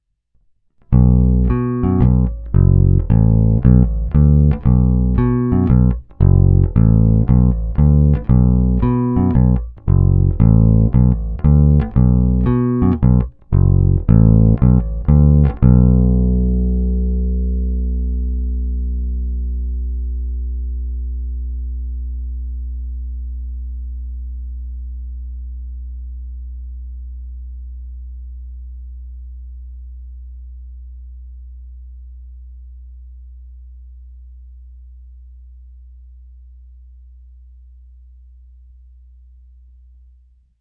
Zvuk je opravdu šedesátkový, středobasový, ne tak ostrý jako padesátkové nebo sedmdesátkové kousky, ale není ani zahuhlaný.
Není-li uvedeno jinak, následující nahrávky jsou provedeny rovnou do zvukové karty, jen normalizovány, jinak ponechány bez úprav.
Hráno vždy s plně otevřenou tónovou clonou.
Hra nad snímačem